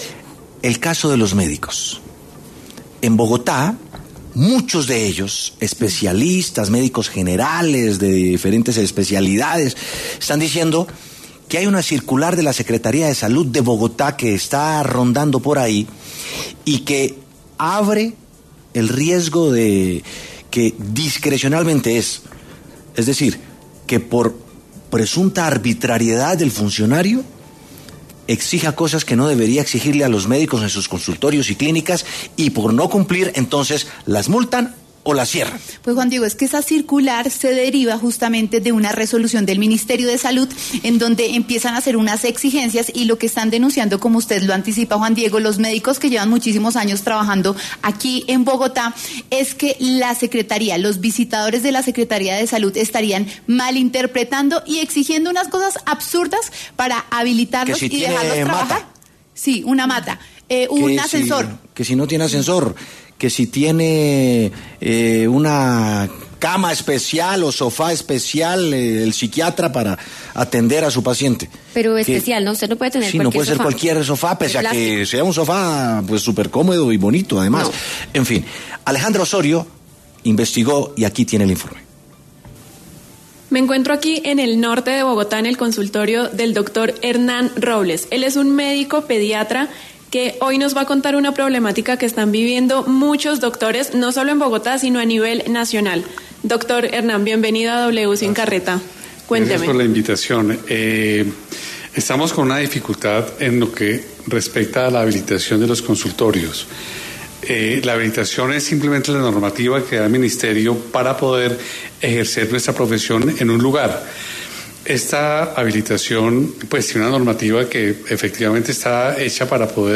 Doctores de Bogotá denuncian en los micrófonos de W Sin Carreta los efectos de la nueva circular del Ministerio de Salud que establece la normativa para el uso de un consultorio médico, advirtiendo que la Secretaría de Salud de la ciudad estaría tomándola de forma subjetiva para ponerles más exigencias de las necesarias.